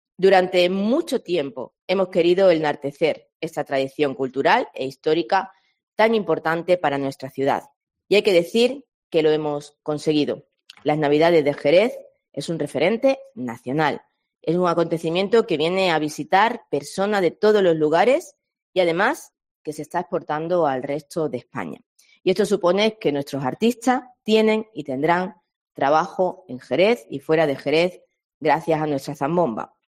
Escucha aquí a la regidora explicando la evolución que, especialmente este año, promueven los atractivos navideños en general y las zambombas en particular respecto al volumen de visitantes a la ciudad en estas fechas.
Escucha a la alcaldesa Mamen Sánchez que valora el crecimiento como atractivo de las zambombas de Jerez